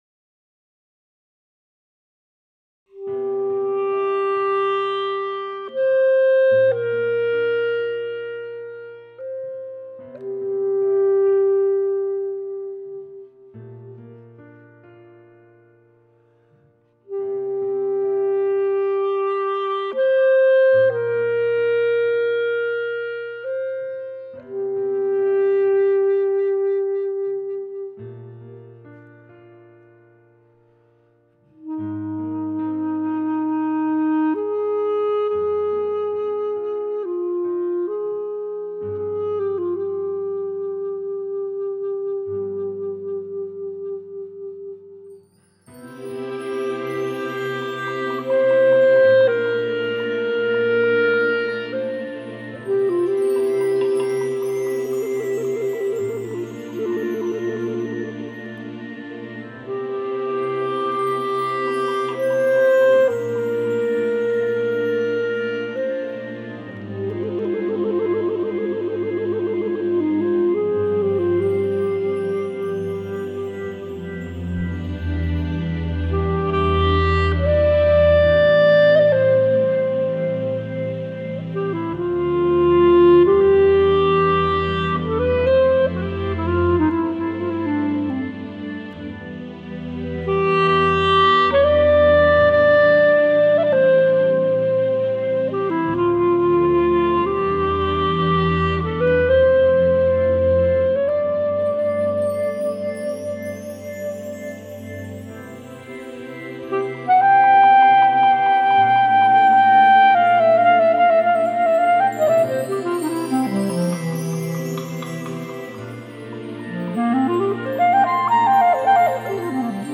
but comfortable relaxation & meditation music setting.
Clarinet and keyboards
Made during Covid 19 Lockdown.